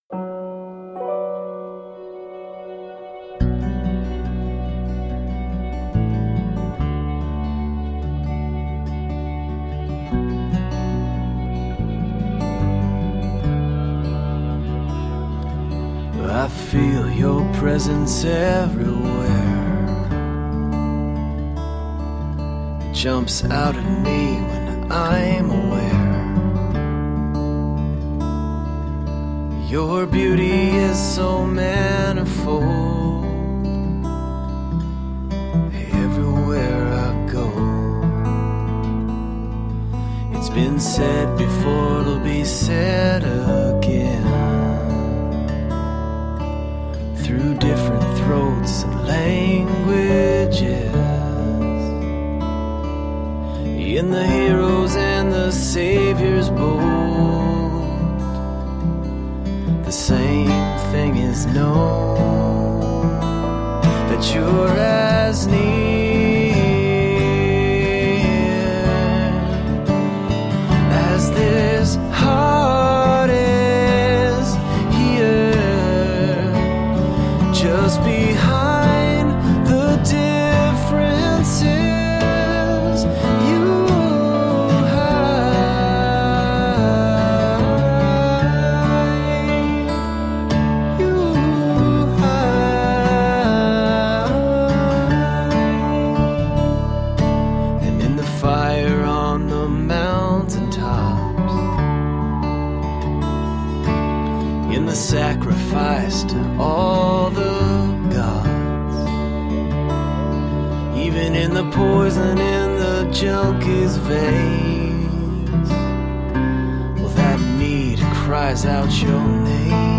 Tagged as: Alt Rock, Folk-Rock